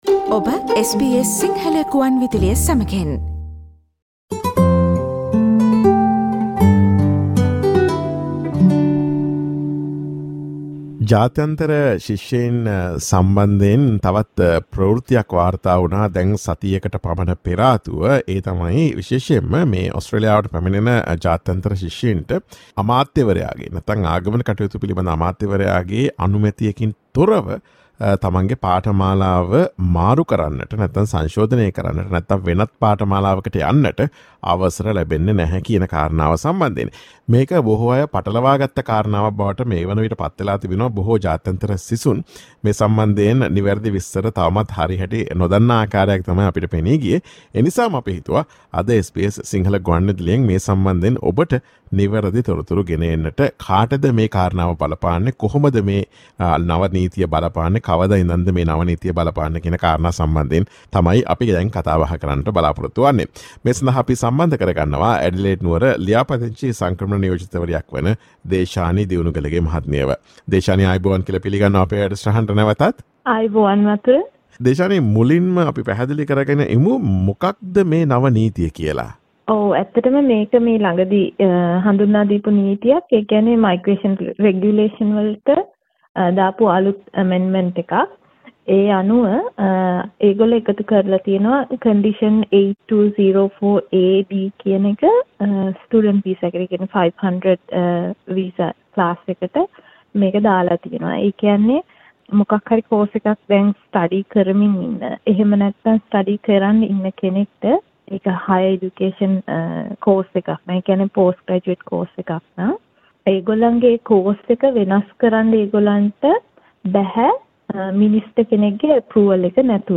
Listen to SBS Sinhala Radio's interview on the latest law that does not allow international students to change courses in Australia.